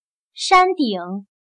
山顶/Shāndǐng/se refiere a la parte más alta de la montaña.